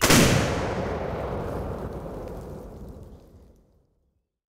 Weapons Demo
assault_rifle_1.wav